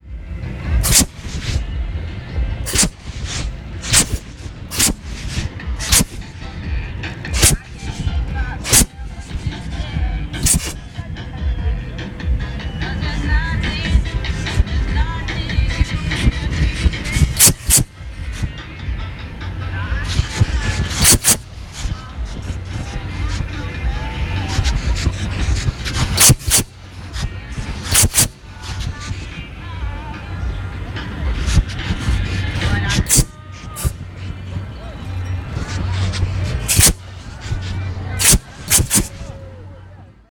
bm_fire_thrower.R.wav